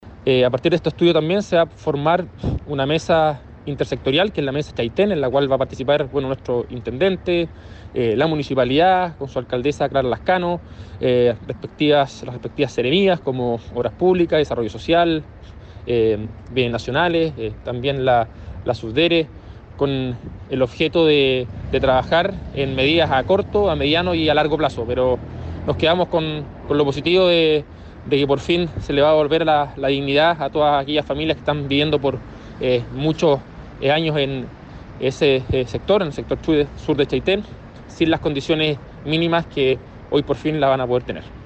El seremi adelantó que se formará una mesa de trabajo en la que estarán representadas la comuna, provincia y región de Los Lagos.